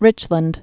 (rĭchlənd)